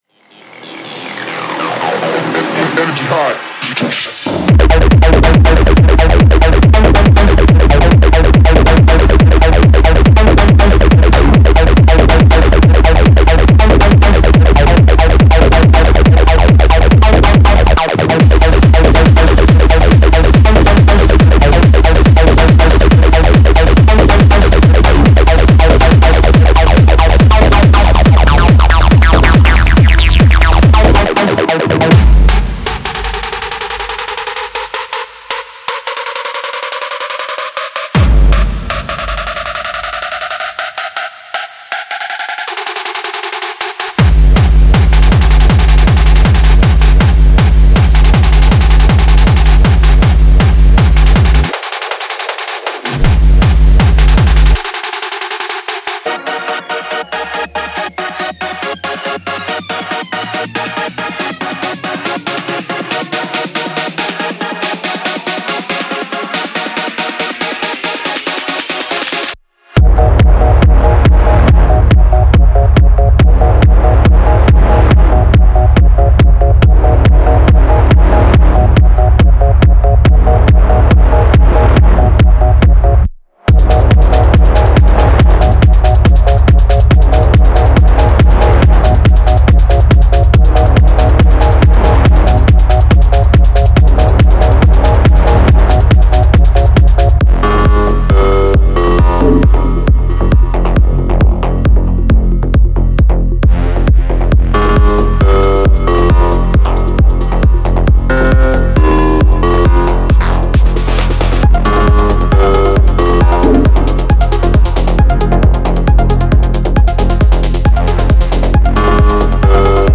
各种类型的电子鼓点（隆隆声、反向贝斯、Schranz）
旋律文件、MIDI、合成器循环、贝斯循环、贝斯单次采样
预录人声、AI人声、人声循环